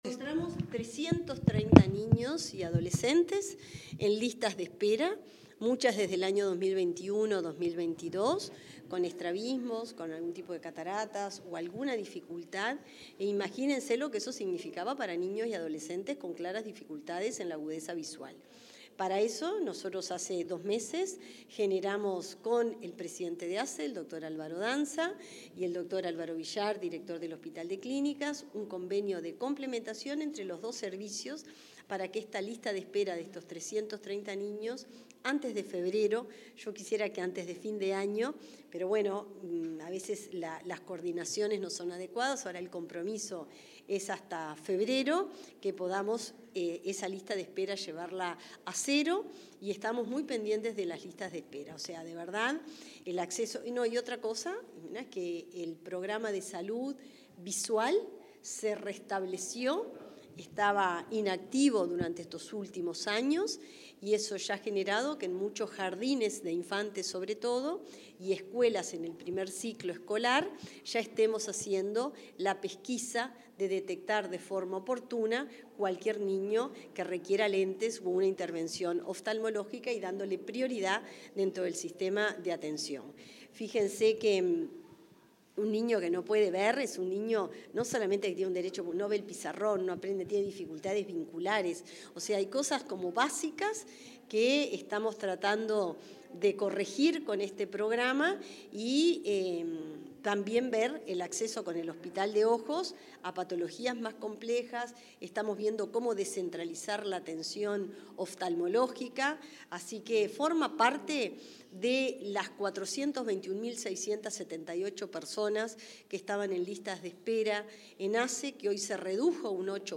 Declaraciones de la ministra Cristina Lustemberg